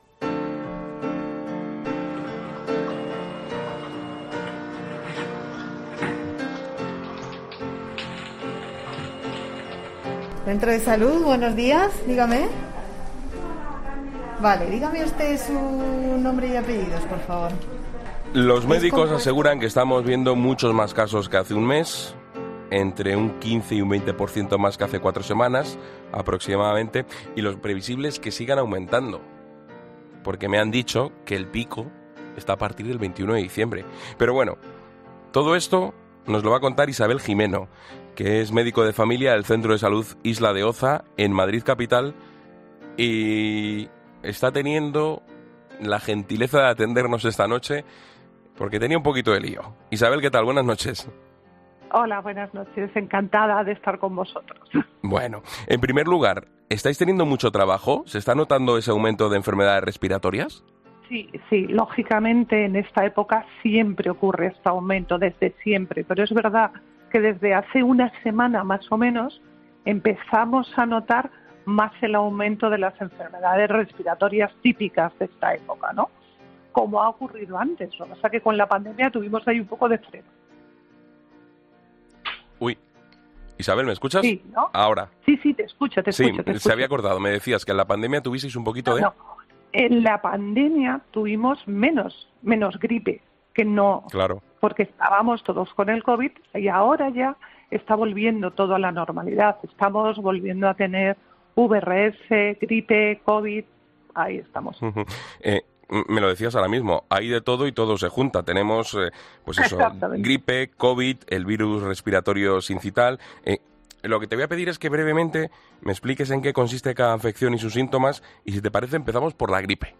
Escucha qué debes hacer si das positivo en gripe, según una médico de familia